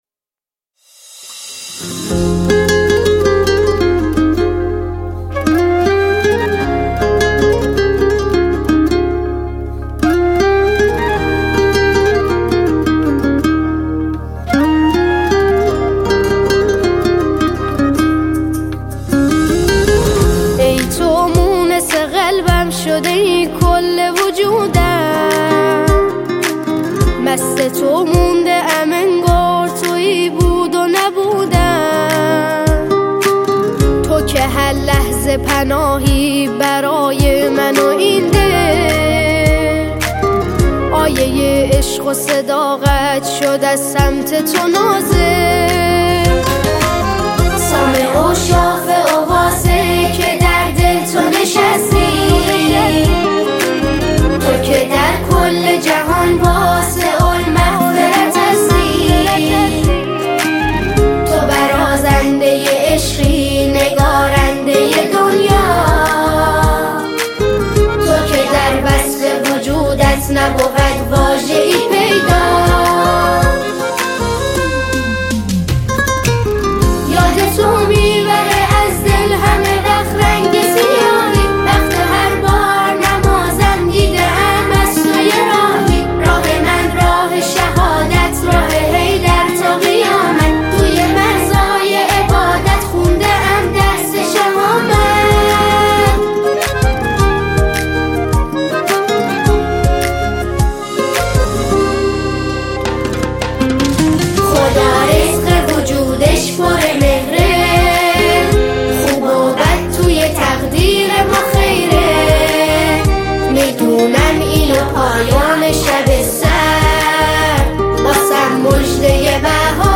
اثری است که آرامش و معنویت را در هم می‌آمیزد
ژانر: سرود ، سرود دانش‌آموزی ، سرود مذهبی ، سرود مناسبتی